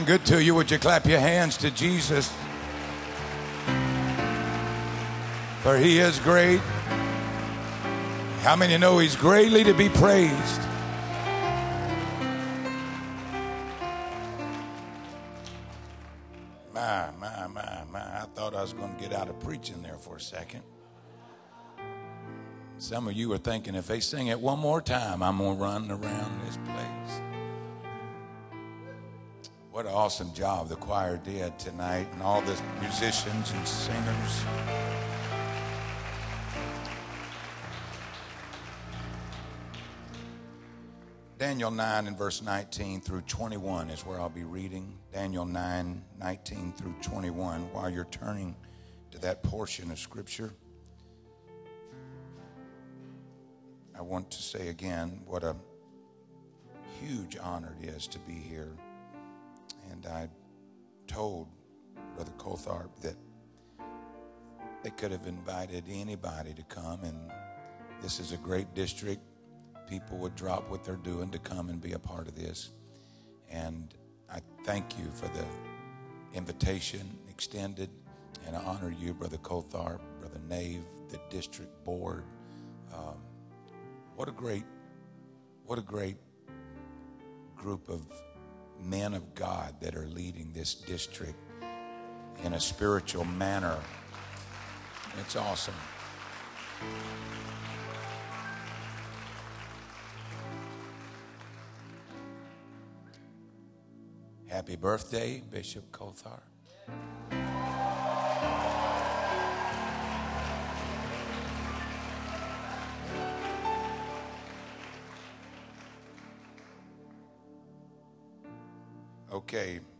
Sermon Archive | Illinois District